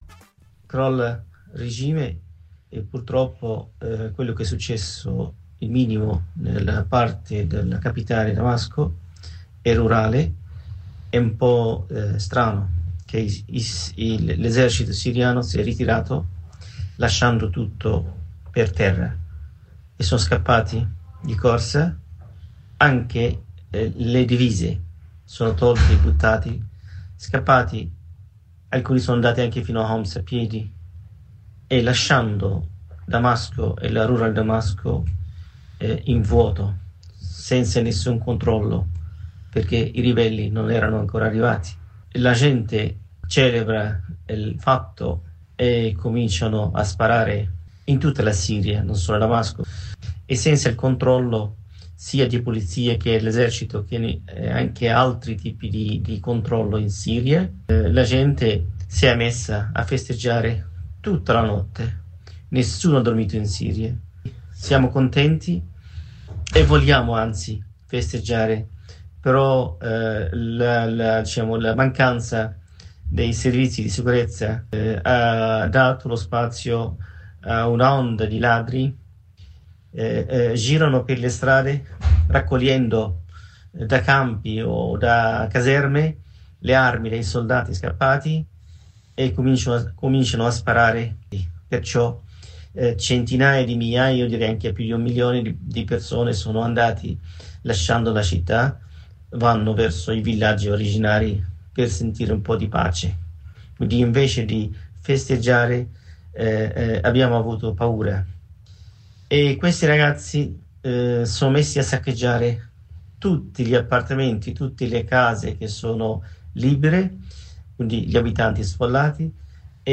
da Damasco ci racconta come si è svegliata la capitale siriana nel primo giorno del cambio di regime, con il contributo di un cittadino di Damasco che lavora nella cooperazione internazionale.